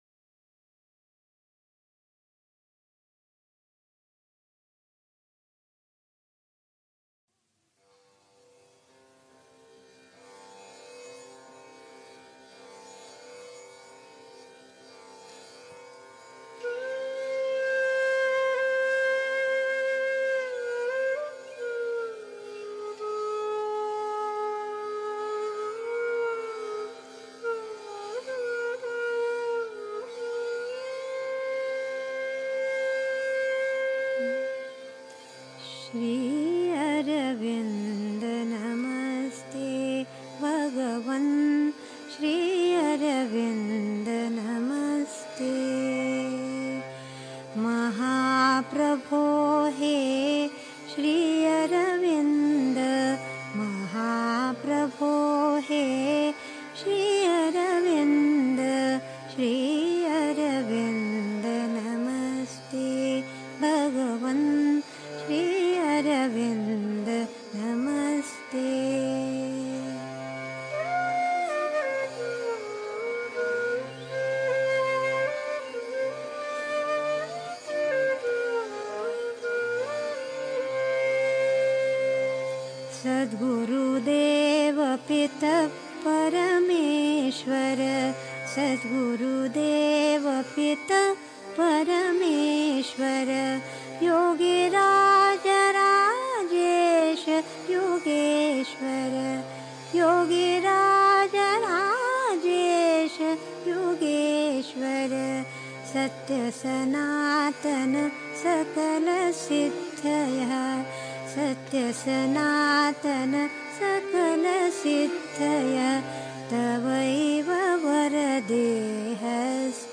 1. Einstimmung mit Musik. 2. Geboten ist ein tagtäglicher Arbeitsglaube an die Macht in uns, die uns ans Ziel bringt (Sri Aurobindo, CWSA, Vol. 23, pp. 774-76) 3. Zwölf Minuten Stille.